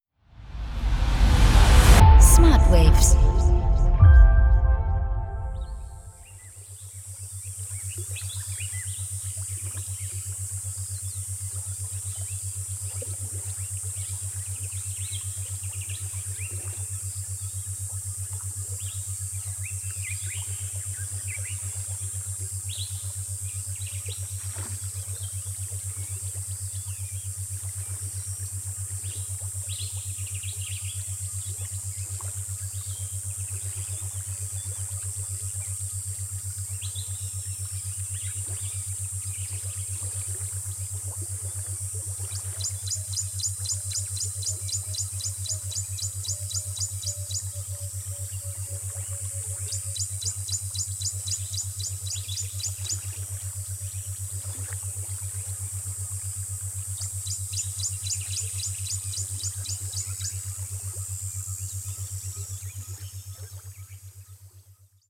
Isochrone Beats